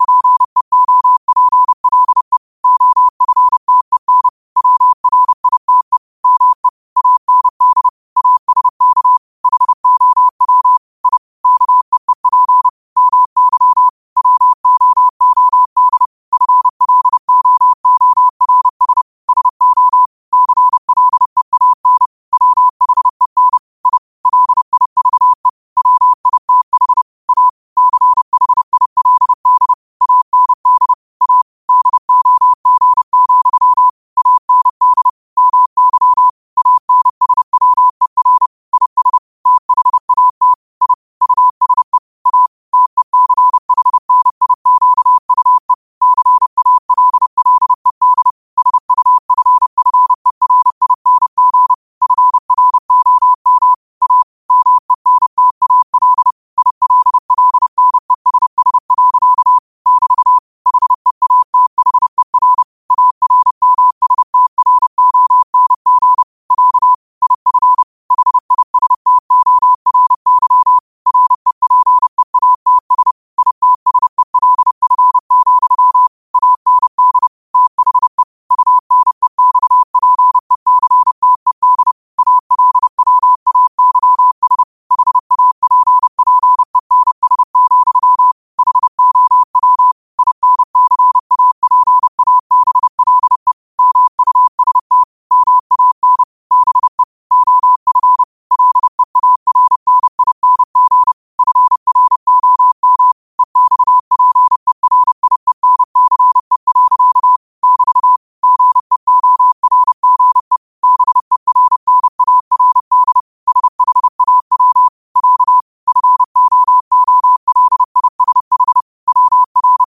Quotes for Mon, 28 Apr 2025 in Morse Code at 30 words per minute.